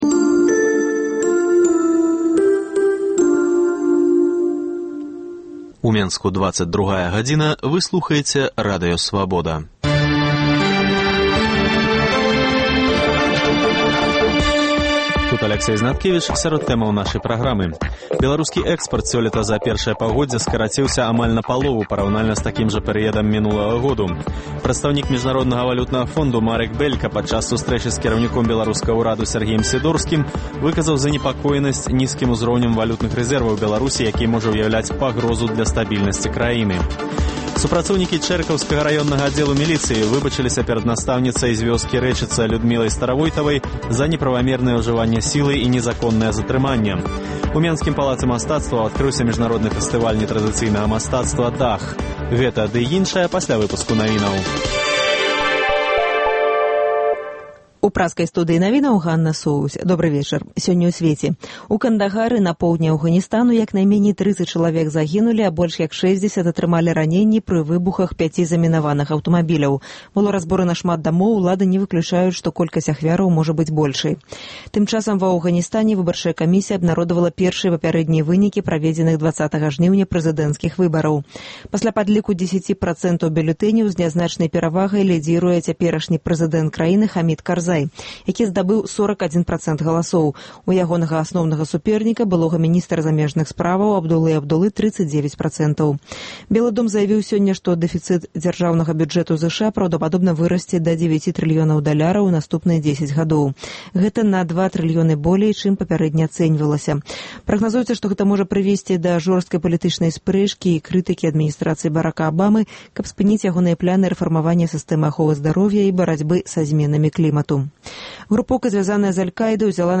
Вечаровы госьць, сацыяльныя досьледы, галасы людзей